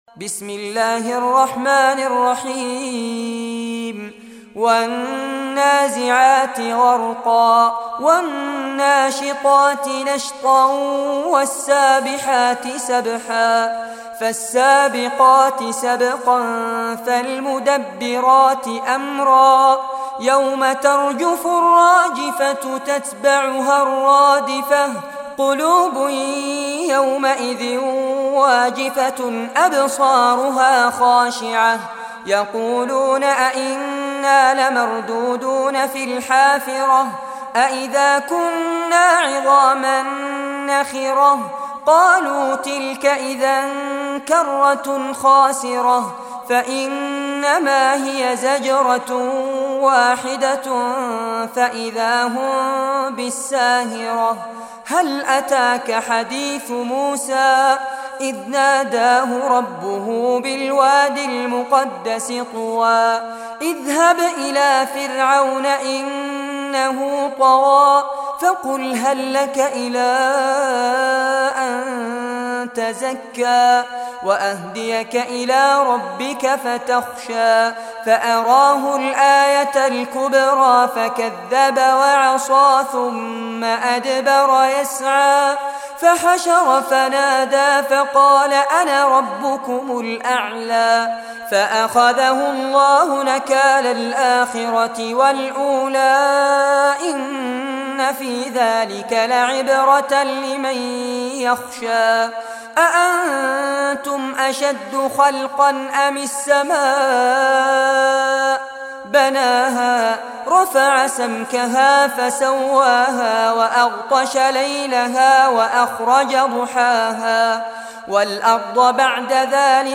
Surah Naziat Recitation